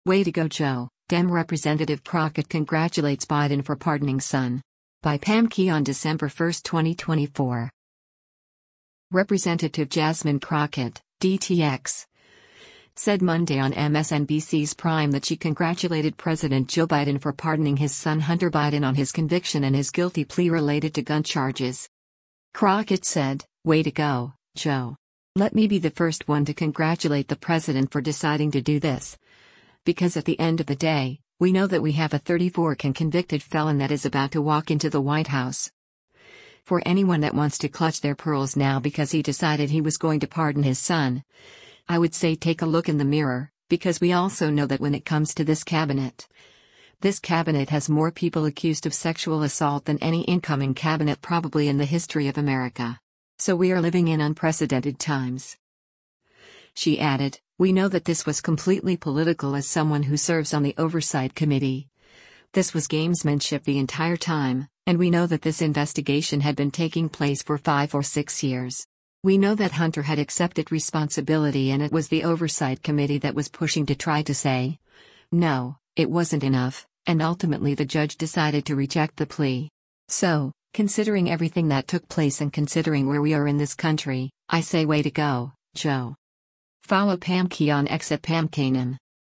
Representative Jasmine Crockett (D-TX) said Monday on MSNBC’s “Prime” that she congratulated President Joe Biden for pardoning his son Hunter Biden on his conviction and his guilty plea related to gun charges.